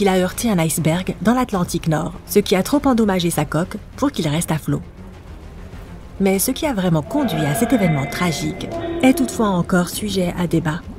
I have an energetic, dynamic and conversational delivery style. With my theater background I can go from the extreme goofy to the seriously intense depending on the script.